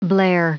Prononciation du mot blare en anglais (fichier audio)
Prononciation du mot : blare